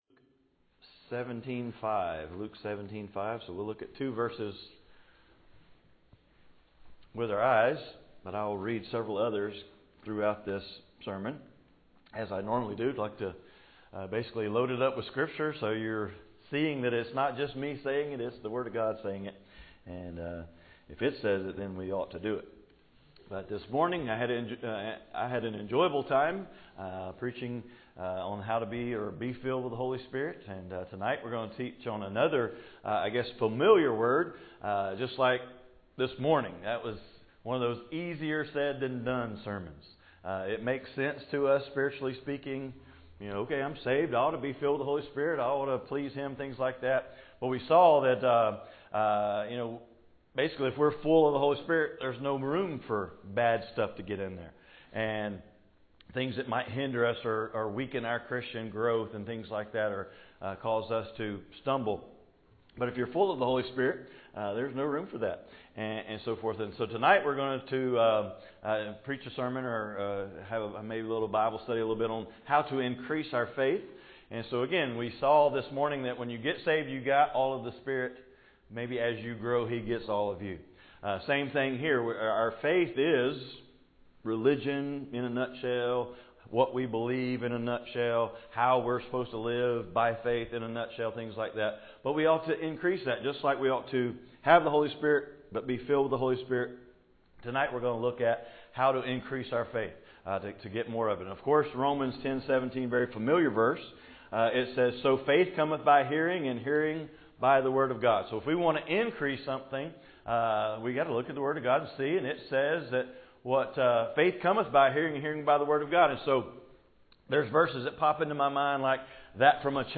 Passage: Luke 17:5 Service Type: Evening Service